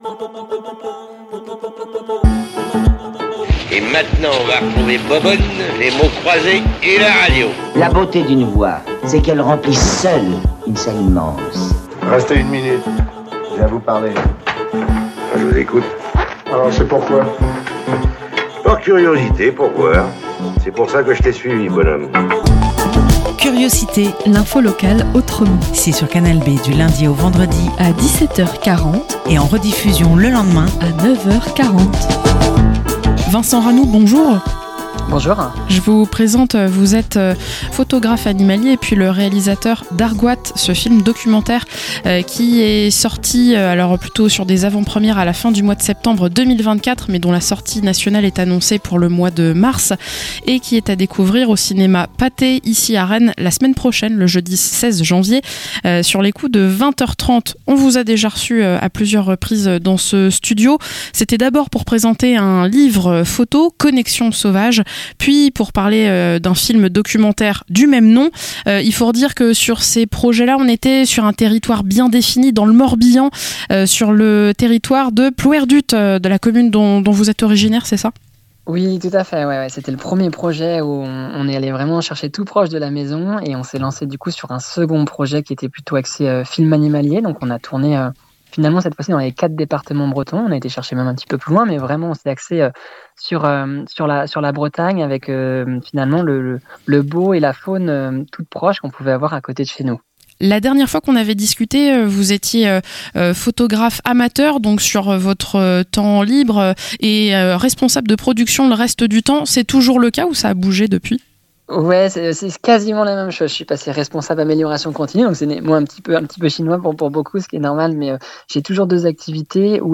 - Interview